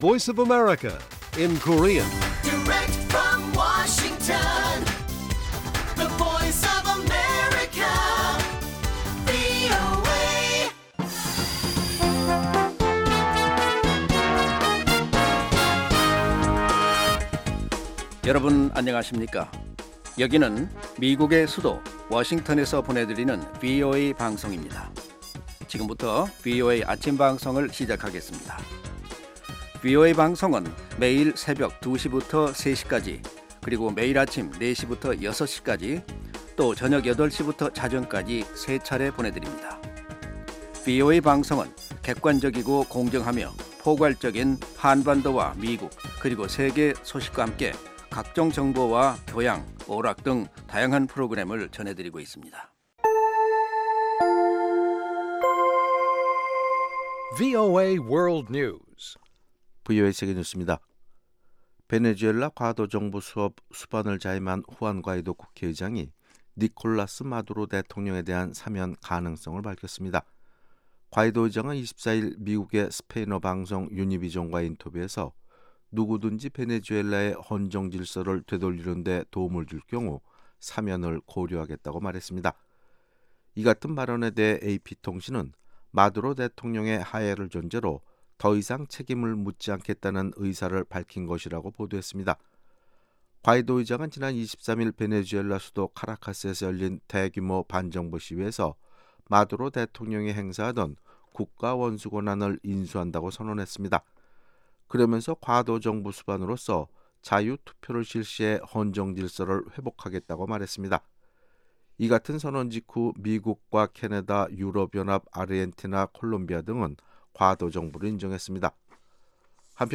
생방송 여기는 워싱턴입니다 1/26 아침
세계 뉴스와 함께 미국의 모든 것을 소개하는 '생방송 여기는 워싱턴입니다', 2019년 1월 26일 아침 방송입니다. ‘지구촌 오늘’은 미국 정부가 극심한 정국 혼란을 겪고 있는 베네수엘라를 돕기 위해 2천만 달러의 인도주의 지원금을 제공할 계획이 있다고 마이크 폼페오 미 국무장관이 밝혔다는 소식, ‘아메리카 나우’에서는 러시아 스캔들을 수사하는 로버트 뮬러 특검이 트럼프 대통령 참모였던 로저 스톤 씨를 기소했다는 이야기를 전해드립니다.